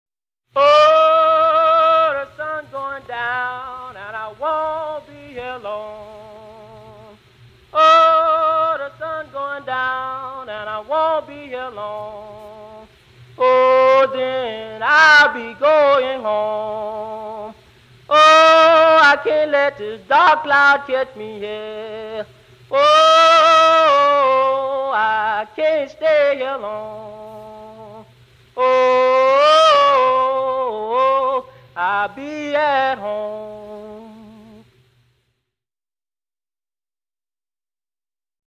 Appels à travers champs